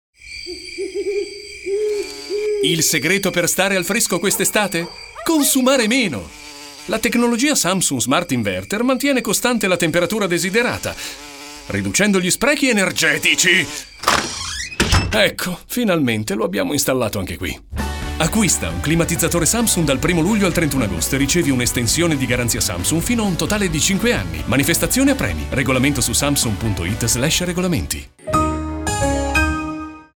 spot Samsung.